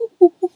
pgs/Assets/Audio/Animal_Impersonations/monkey_2_chatter_17.wav at master
monkey_2_chatter_17.wav